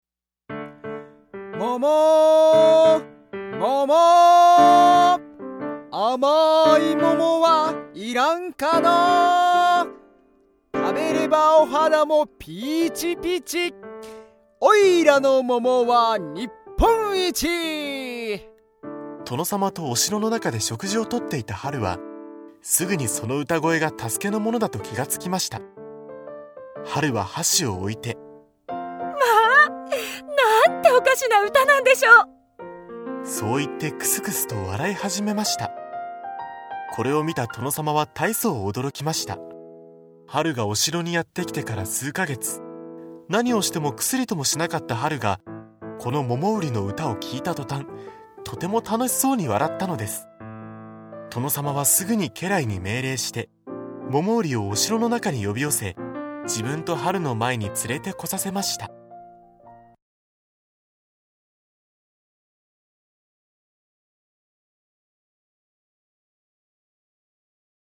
大人も子どもも楽しめる、童話オーディオブック！
大人も子どもも一緒になって、多彩なキャストと、楽しい音楽でお楽しみ下さい。